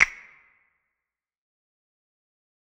Percs
Destroy - Shake Snap.wav